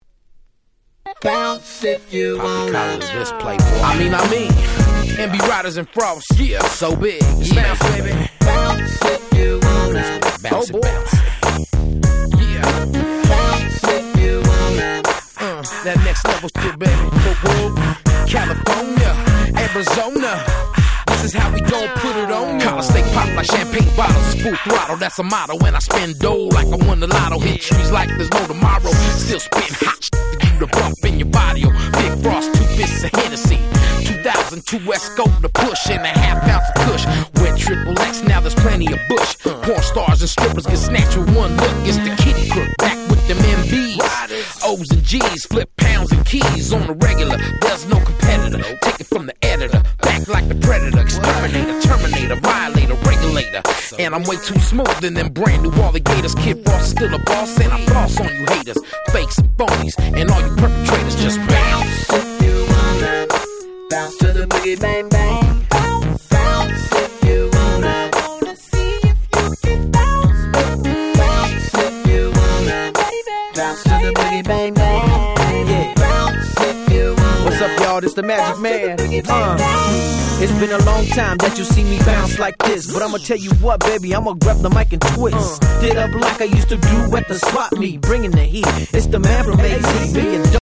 04' Nice West Coast Hip Hop / G-Rap / Chicano Rap !!
ウエッサイ ウエストコーストヒップホップ ジーラップ チカーノ トークボックス